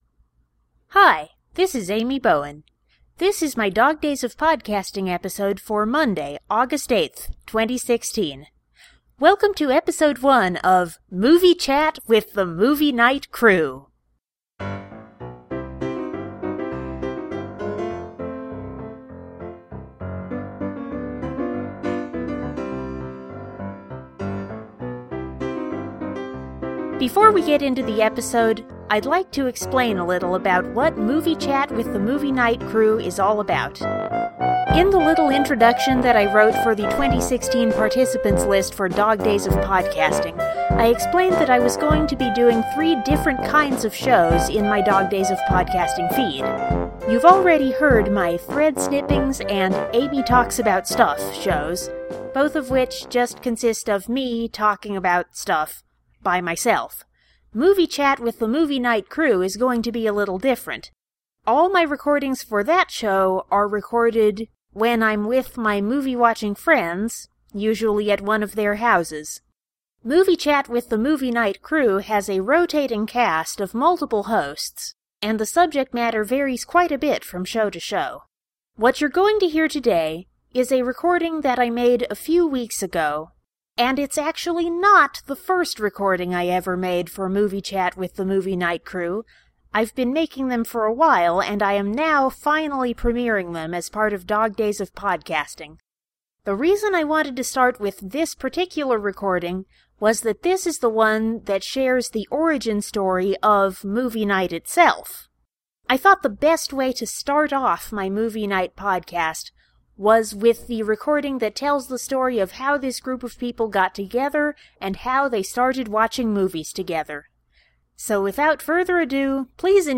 Unlike the other shows I’m recording for Dog Days of Podcasting, Movie Chat with the Movie Night Crew is co-hosted by me and a rotating cast of my friends. We often get together to watch movies, and I often record the discussions we have before and after the movies.